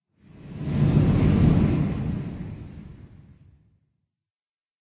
cave11.ogg